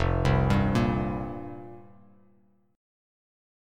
Fsus2sus4 chord